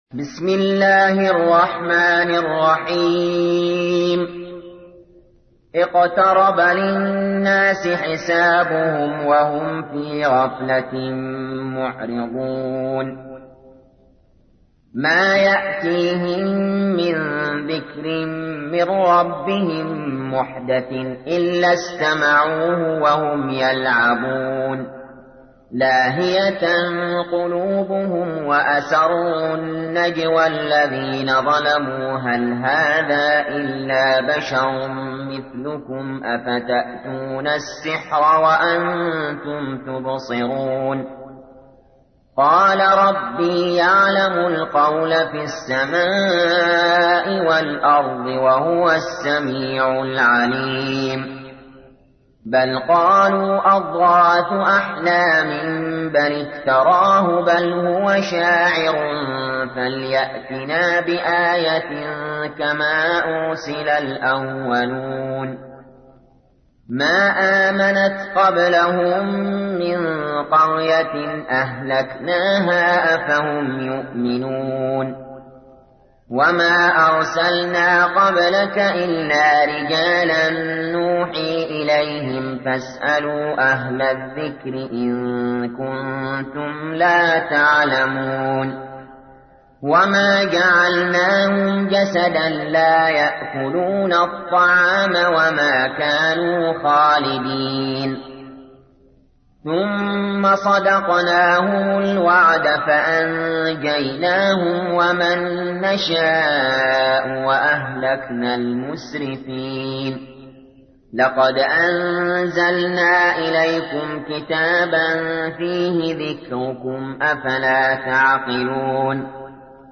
تحميل : 21. سورة الأنبياء / القارئ علي جابر / القرآن الكريم / موقع يا حسين